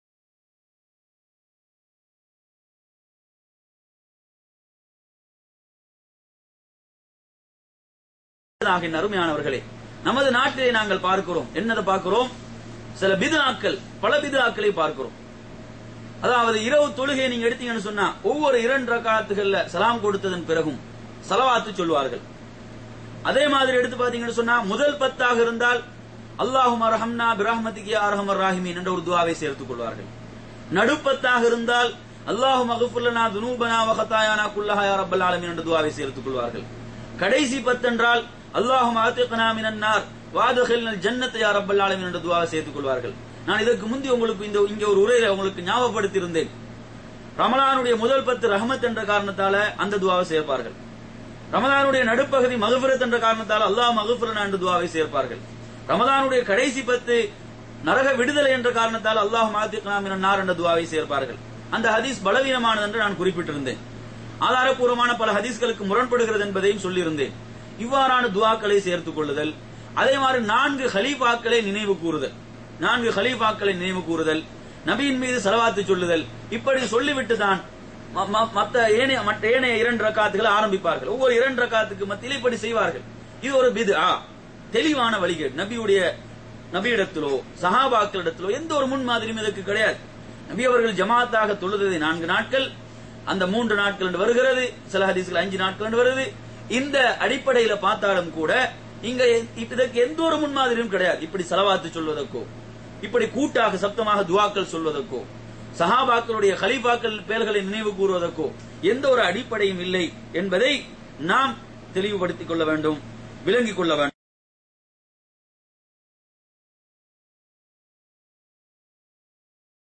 நிகழ்ச்சி : சிறப்பு பயான் நிகழ்ச்சி
இடம் : அல்-கப்ஜி அழைப்பு மற்றும் வழிகாட்டல் மையம், அல்-கப்ஜி, சவூதி அரேபியா